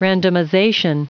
Prononciation du mot randomization en anglais (fichier audio)
Prononciation du mot : randomization